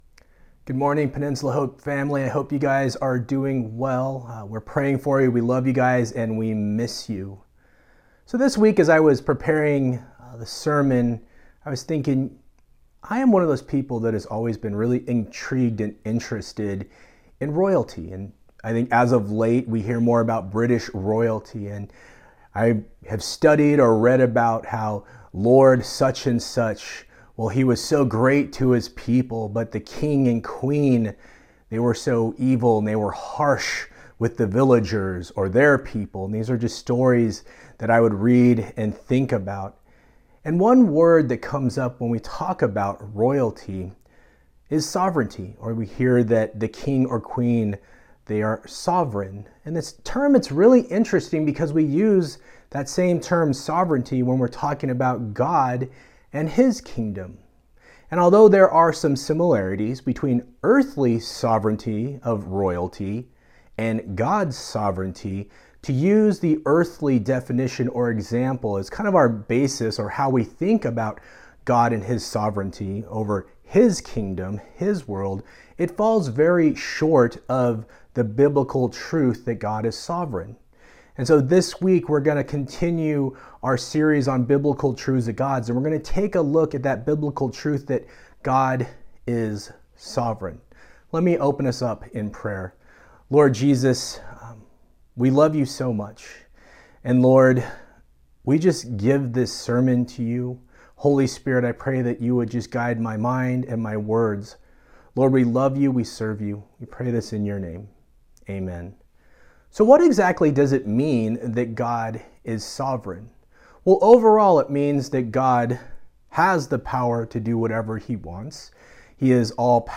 October 18, 2020 Sunday Message